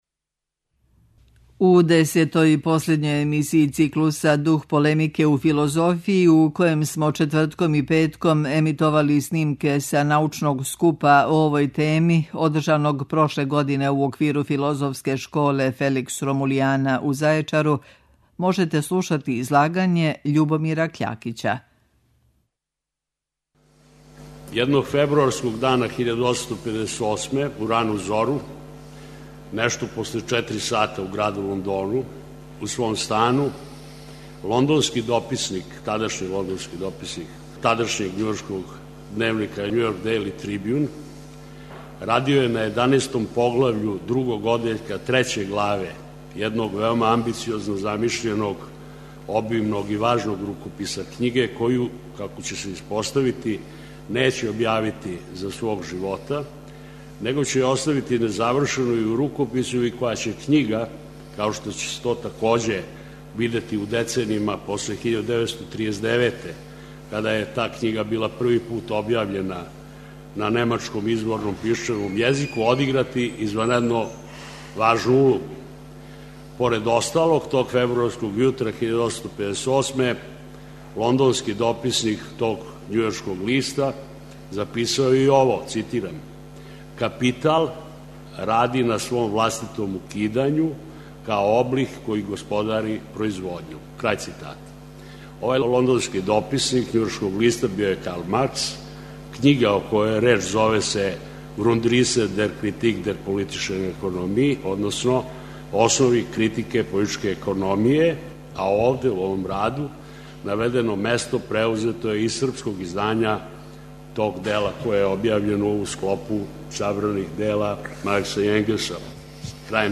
Научни скупови
Прошлог лета у Зајечару одржана је шеснаеста Филозофска школа Felix Romuliana, научни скуп са традицијом дугом 22 године.